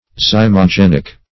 Zymogenic \Zym`o*gen"ic\, a. (Biol.)